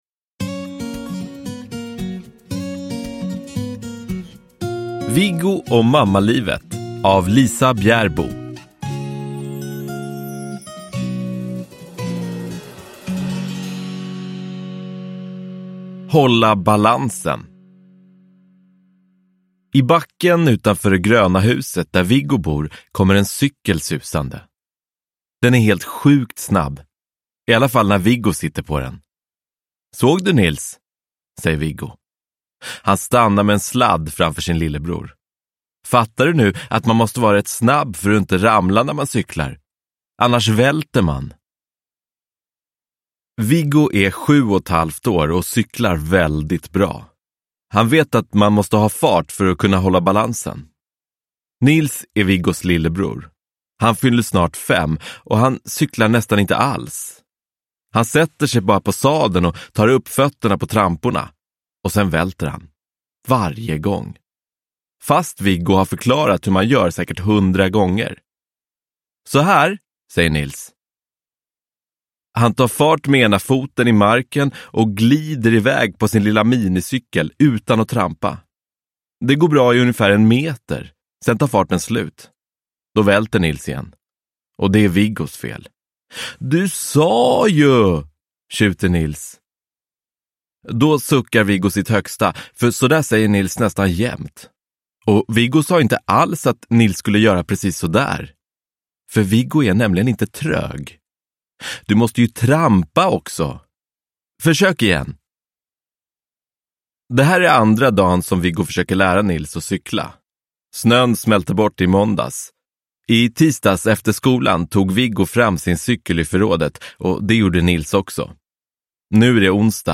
Viggo och mammalivet – Ljudbok – Laddas ner